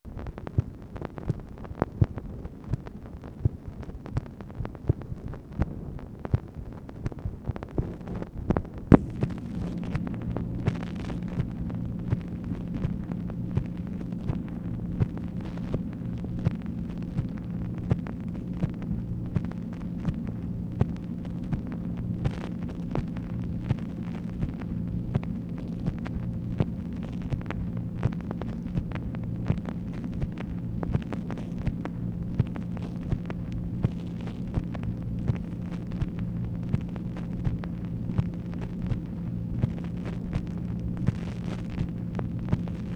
MACHINE NOISE, January 21, 1966
Secret White House Tapes | Lyndon B. Johnson Presidency